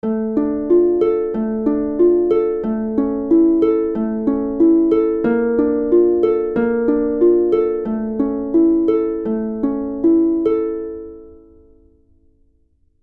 Here's what P-i-m-a 2X looks and sounds like when applied to the chords in the first line of Andante Opus 31 No. 4: